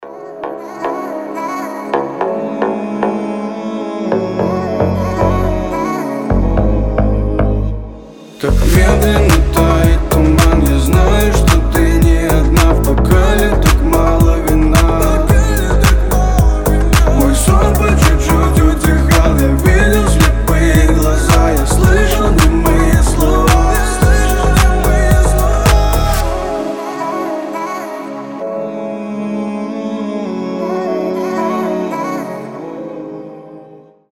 лирика
грустные
красивый мужской голос
детский голос